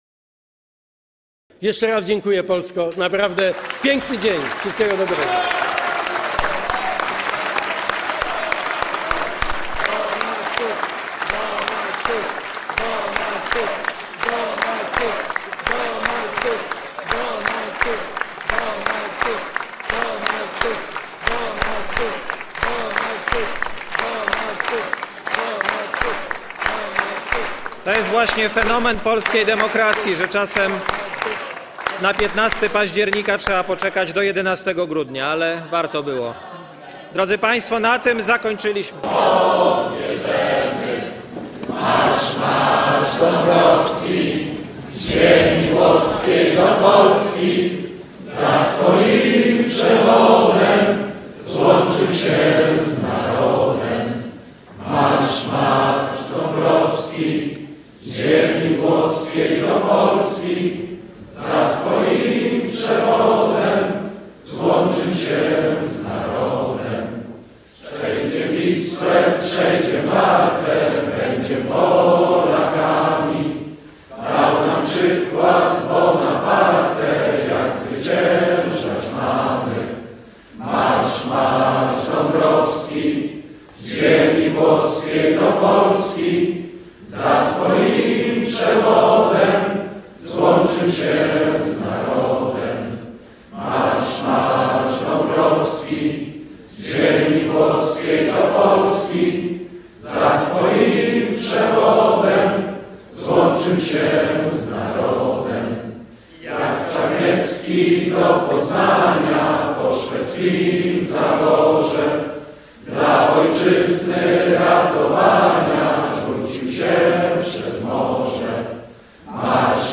Ci, których dzięki dotychczas milczącym żeśmy wybrali,  na początek swych rządów spontanicznie Hymn  Polski zaśpiewali.
Hymn-Polski-spiewaja-Poslowie-X-kadencji-1.mp3